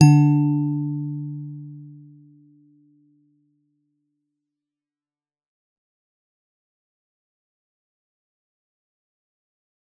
G_Musicbox-D3-f.wav